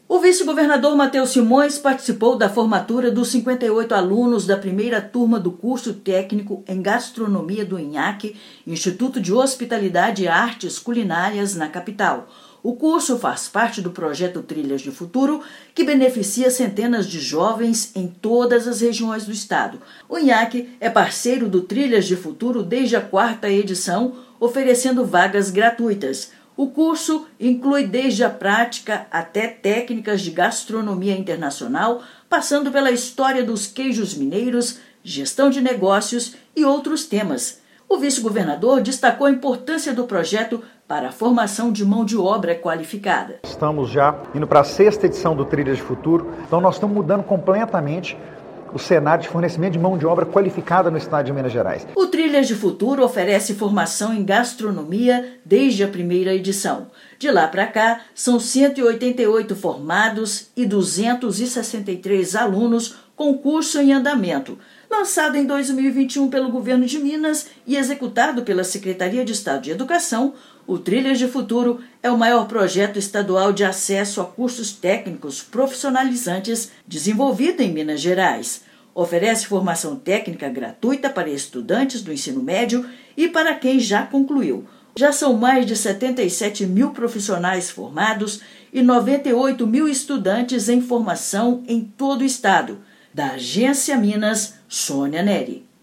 [RÁDIO] Governo de Minas forma 58 estudantes de Curso Técnico em Gastronomia pelo Trilhas de Futuro
Iniciativa do Estado oferece qualificação gratuita para inserção no mercado de trabalho; já são mais de 77 mil profissionais formados desde 2021. Ouça matéria de rádio.